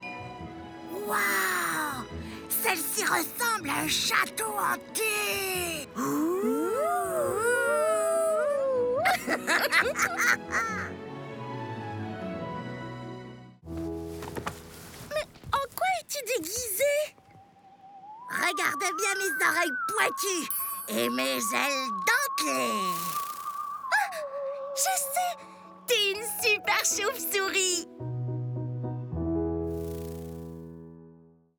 Livre audio jeunesse – Personnages